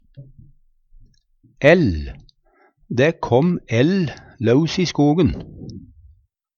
DIALEKTORD PÅ NORMERT NORSK ell ild, varme Eintal ubunde Eintal bunde Fleirtal ubunde Fleirtal bunde æll ællen Eksempel på bruk Dæ kom ell laus i skogen.